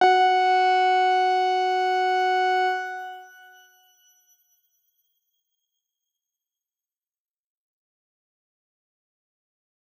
X_Grain-F#4-pp.wav